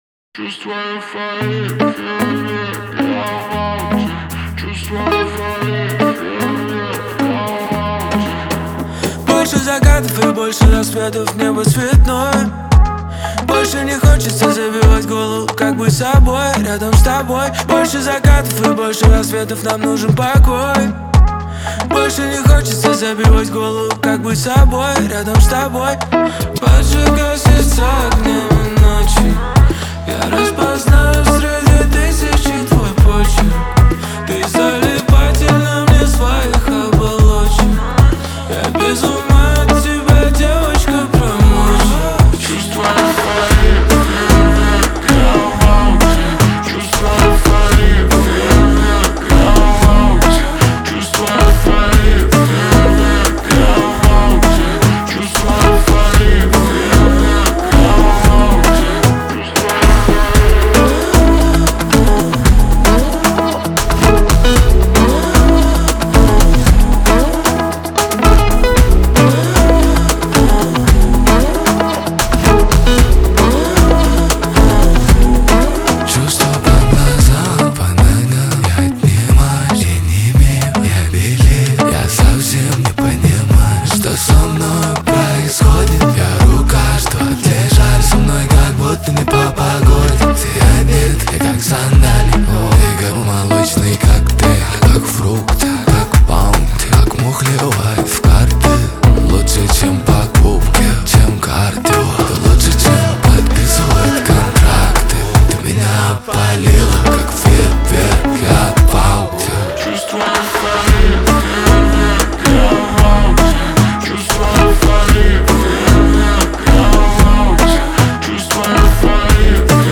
Жанр: Казахские / Русские песни